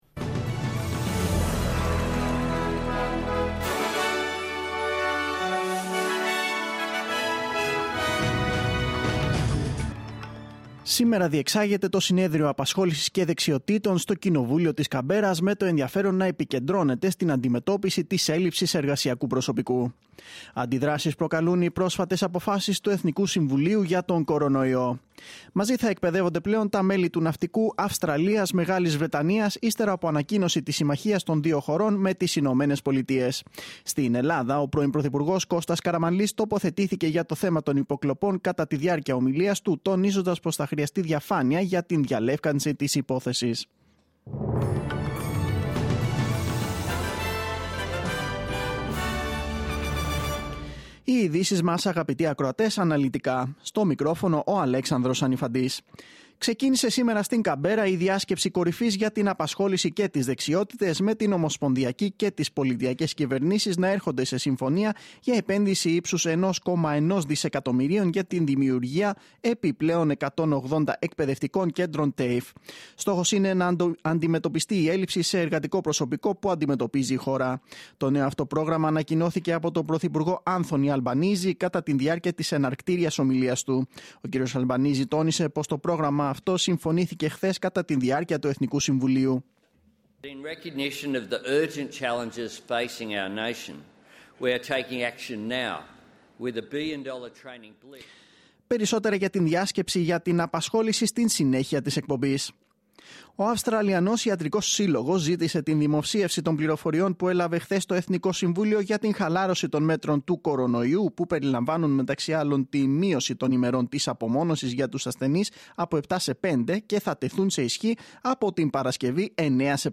Δελτίο Ειδήσεων: Πέμπτη 1-9-2022
News in Greek.